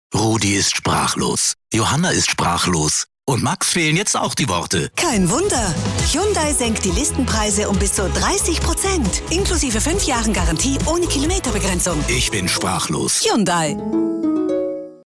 Hyundai "Sprachlos", Radio-Spot